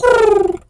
pigeon_2_call_calm_07.wav